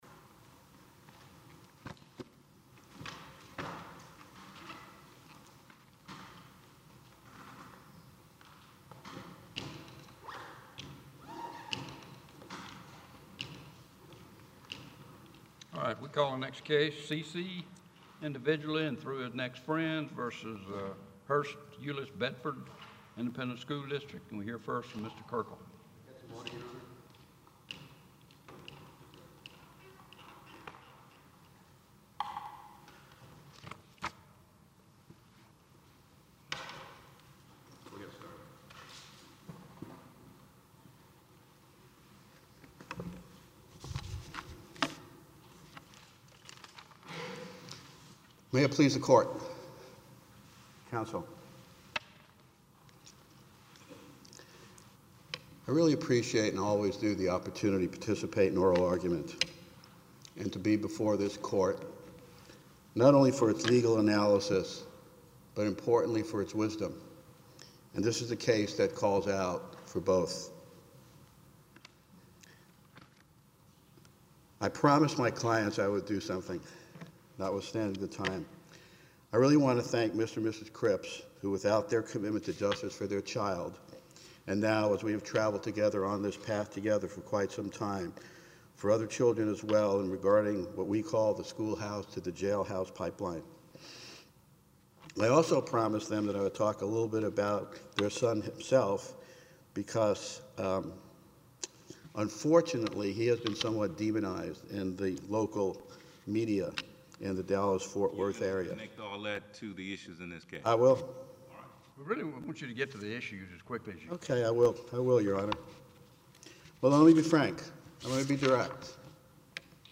Do school districts have the right to not reveiw disciplinary alternative education placements (DAEP) once a law enforcement agency finds that no offense was committed? Home 5th Circuit Case Brief Audio of Oral Argument How can I help?